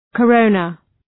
{kə’rəʋnə}